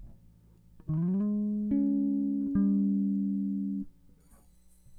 BASSLICK.WAV